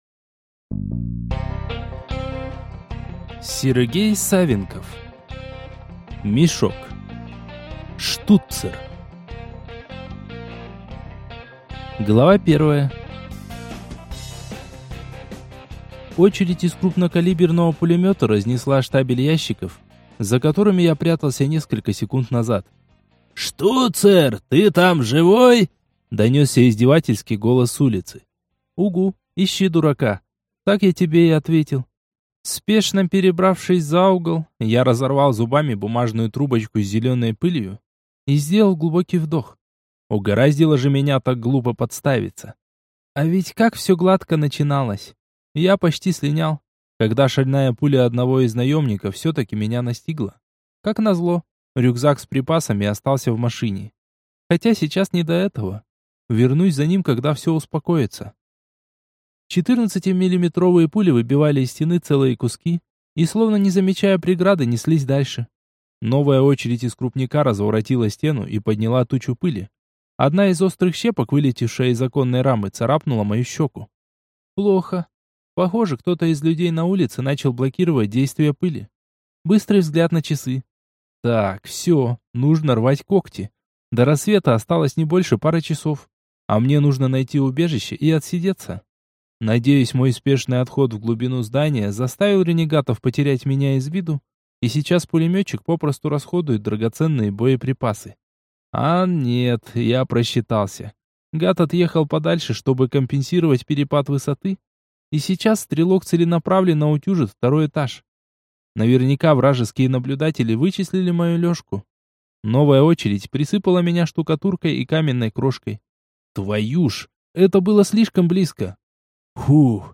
Аудиокнига Мешок. Штуцер | Библиотека аудиокниг